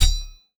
etfx_target_hit.wav